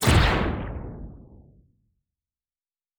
Sci Fi Explosion 05.wav